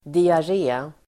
Uttal: [diar'e:]